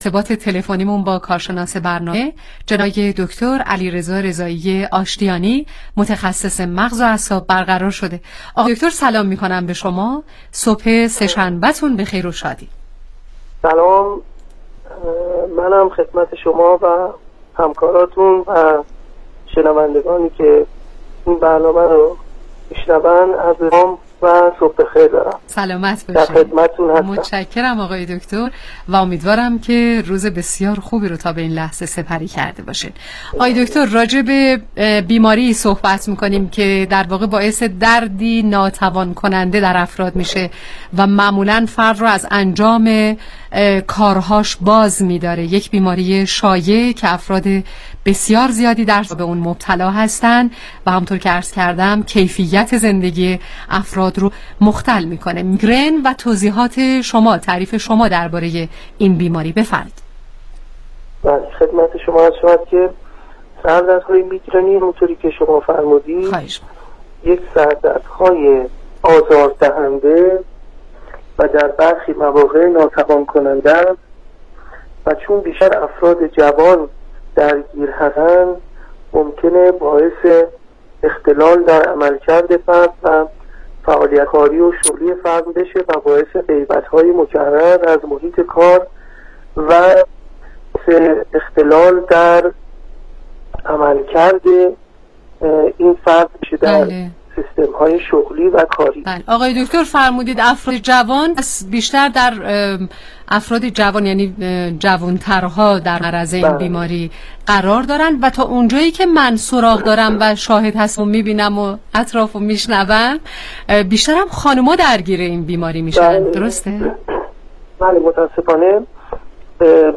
برنامه رادیویی کانون مهر با موضوع میگرن